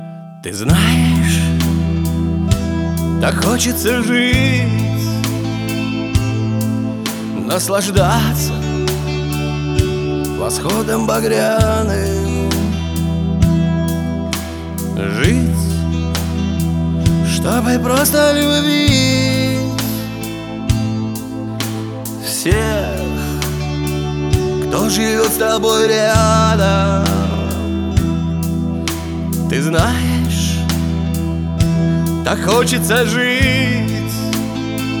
Жанр: Шансон / Русские